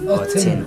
[ot͡sen] noun winter